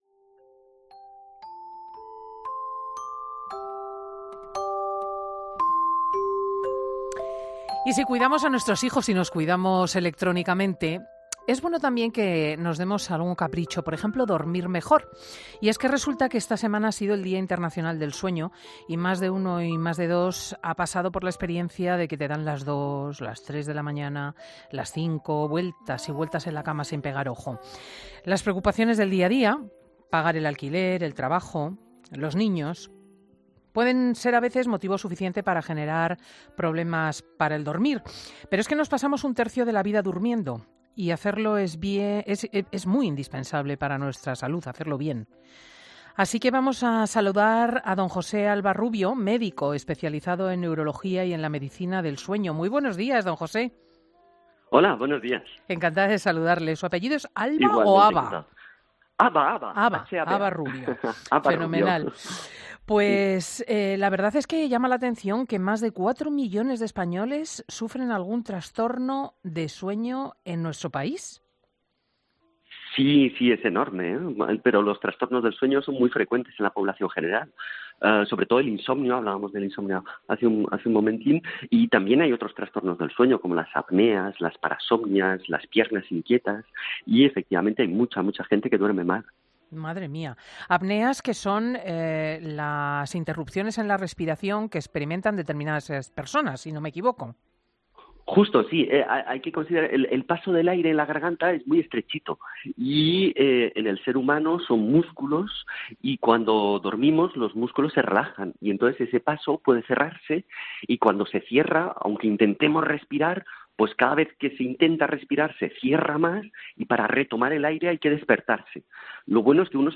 AUDIO: ¿Das vueltas y vueltas y más vueltas cuando te metes en la cama incapaz de dormir? Hemos hablado con un experto del sueño en Fin de Semana.